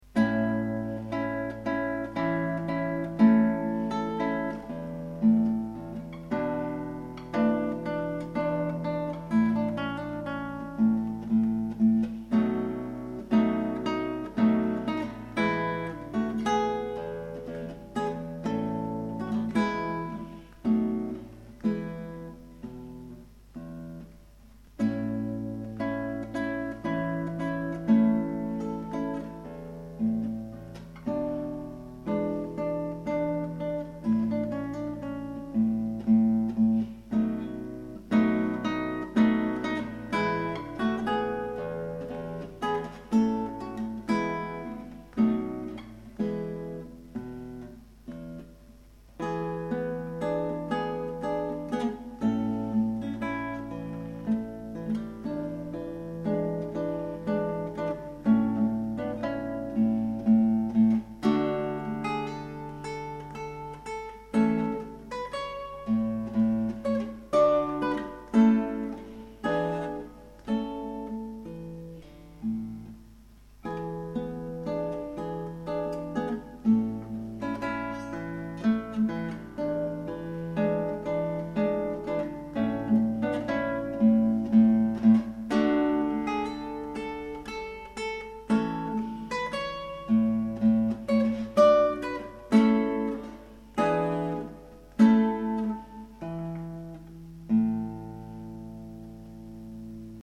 - Guitare Classique
Et à l'intérieur, ce petit menuet qui est joué discrètement dans un célèbre opéra d'un non moins célèbre compositeur.
menuet_648.mp3